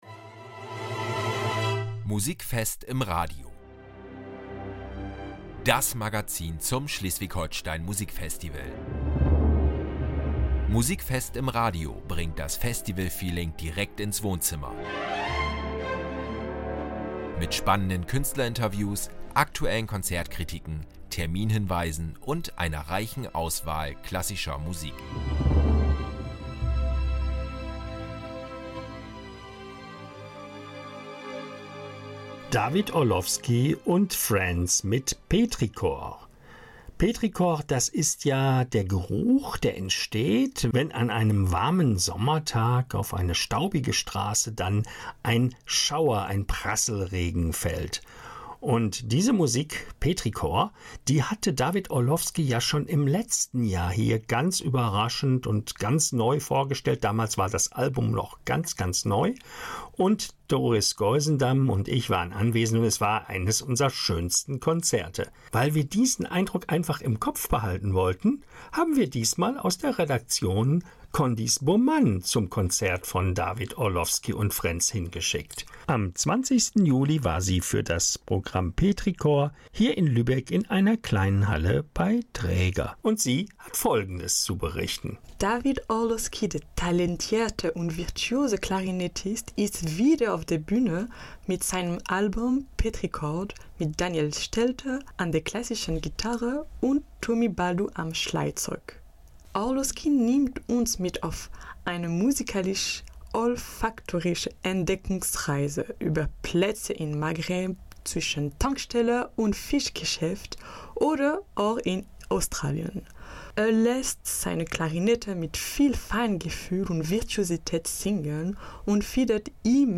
Auch das Musikfest in Wotersen wird noch einmal lebendig. Im Interview: das genreübergreifende “Orchester im Treppenhaus”, das Klassik ganz neu denkt.